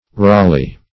Search Result for " rolley" : The Collaborative International Dictionary of English v.0.48: Rolley \Roll"ey\ (-[y^]), n. [Probably fr. roll.] A small wagon used for the underground work of a mine.